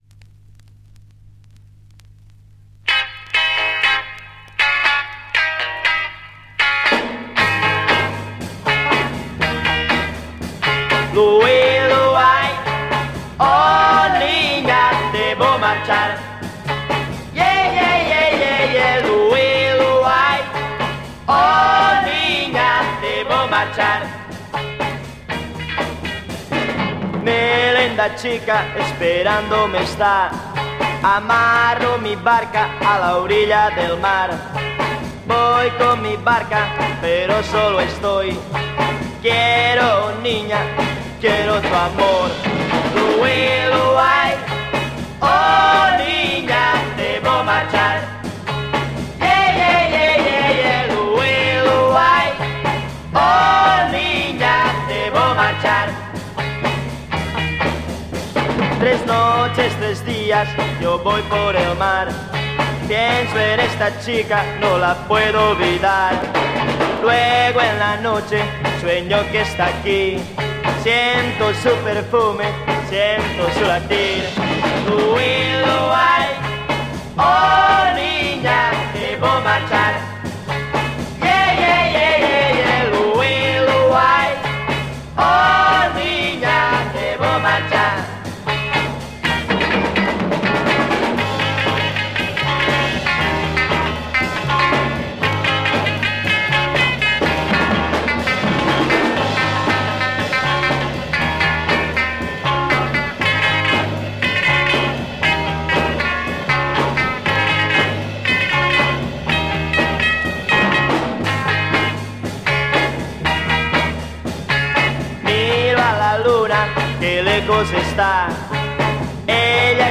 Mega rare Spanish EP Garage Freakbeat